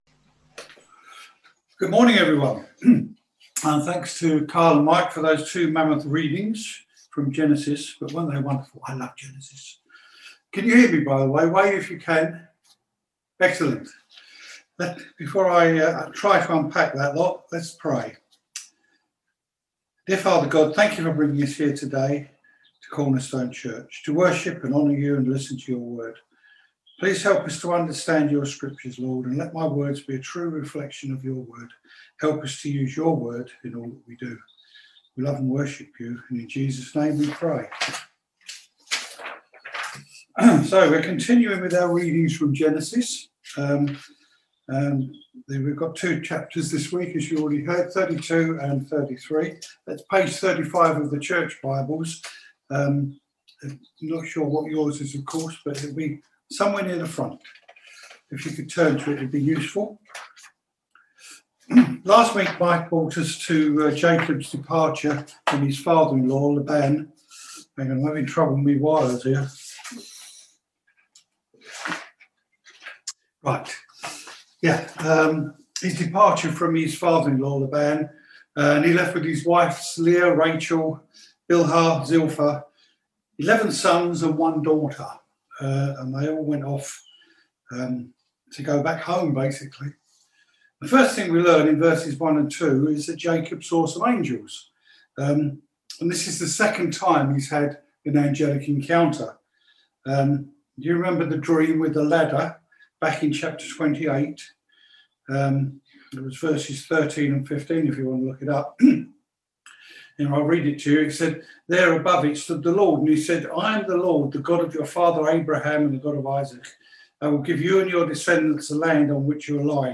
Genesis 32v1-33v20 Service Type: Sunday Morning Service Topics